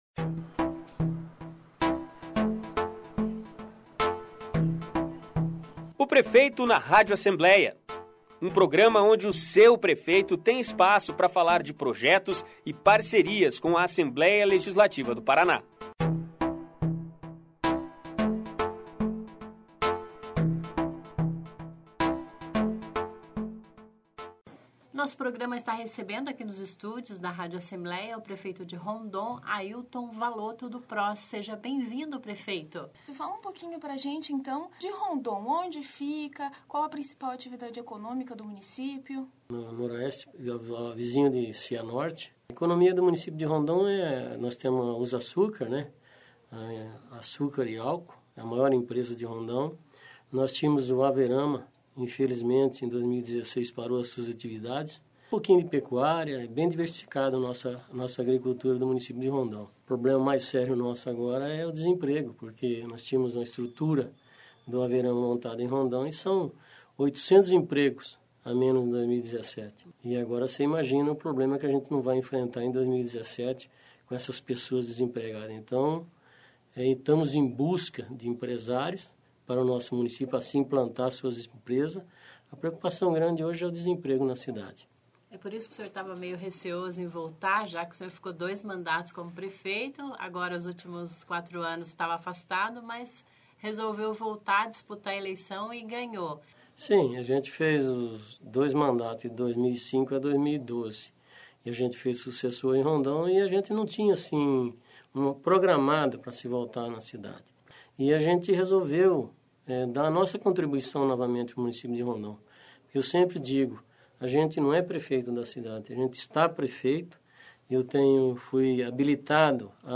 O prefeito de Rondon, Aílton Valoto (PROS) conversou com a gente sobre projetos , necessidades e desafios no mandato,  que começou em janeiro de 2017. Ouça a entrevista.